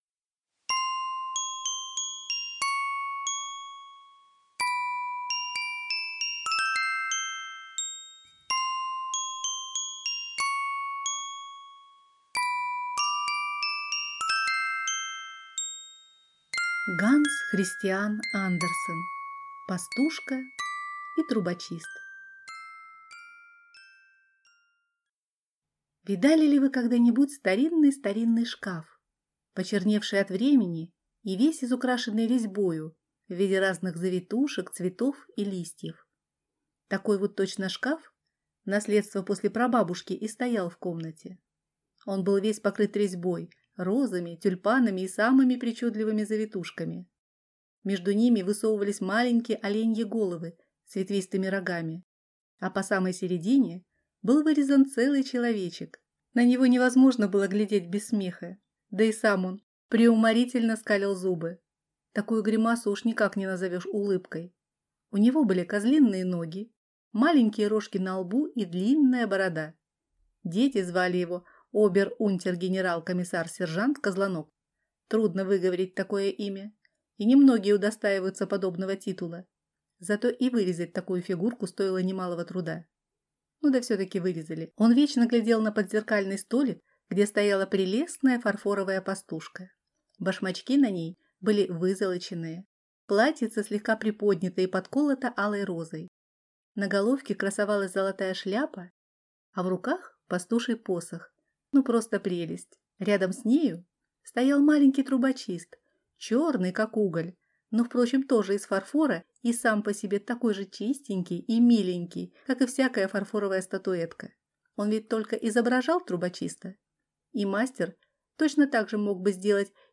Аудиокнига Пастушка и трубочист | Библиотека аудиокниг